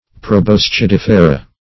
Proboscidifera \Pro*bos`ci*dif"e*ra\, n. pl. [NL. See